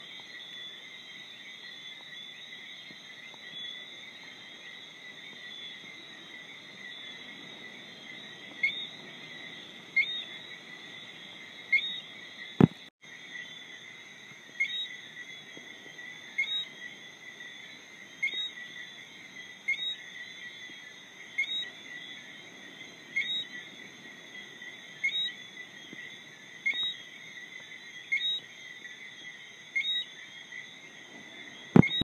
Mit ihrem Gepfeife verleihen sie der Nacht auf Bermuda ihren ganz besonderen Reiz und man gewöhnt sich recht schnell an die kleinen Schreihälse.
Eine kleine Hörprobe der Nachtgeräusche in Bermuda gefällig?
New-Recording-3treefrogs.m4a